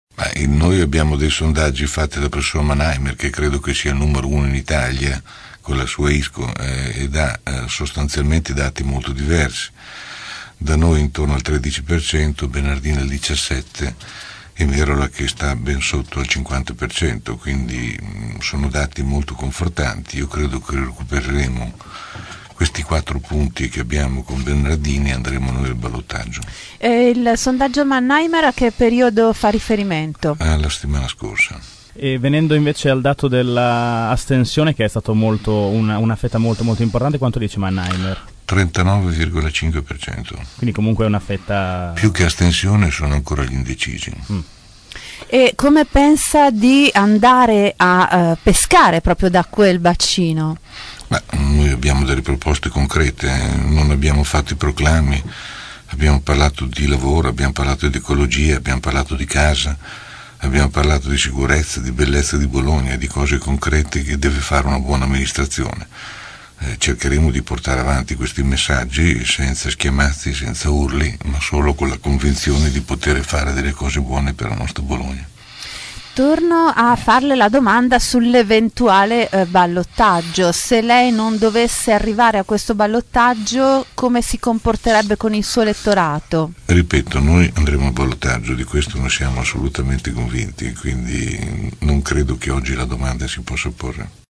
ospite questa mattina dei nostri studi.